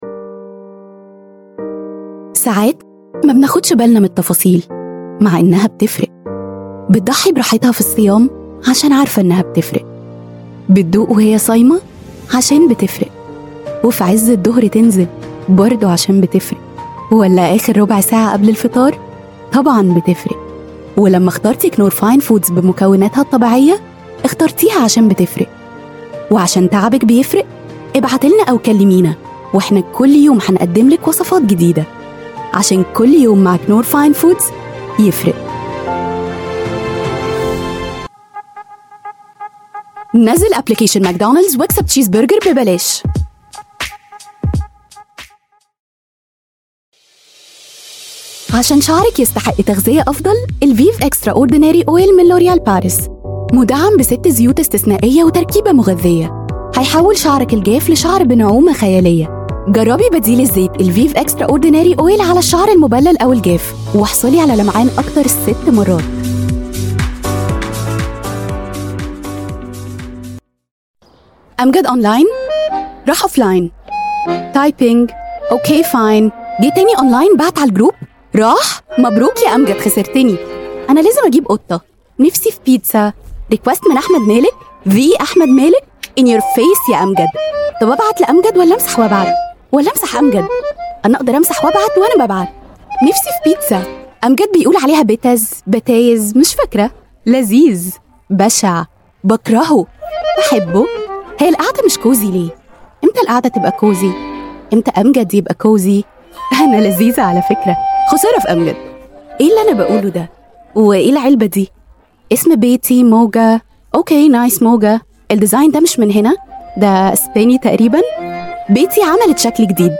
FeMale Voices